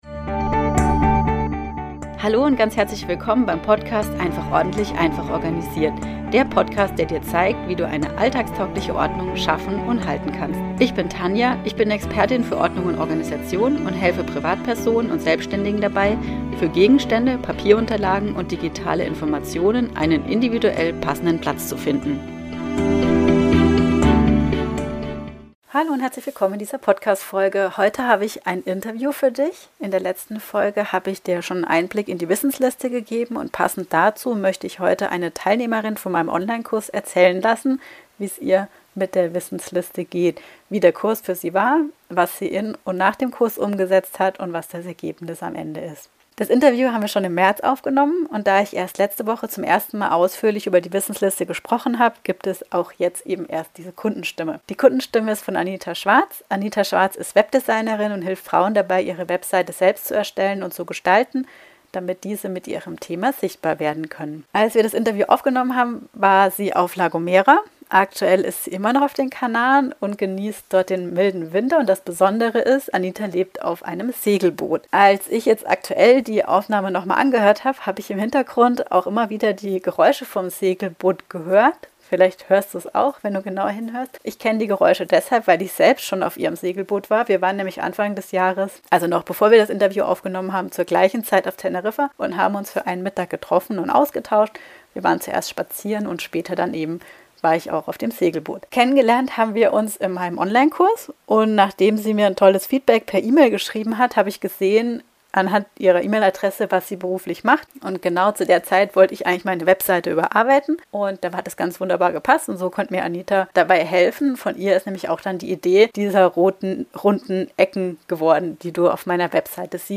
Eine Kundin berichtet von ihren Erfahrungen mit der Wissensliste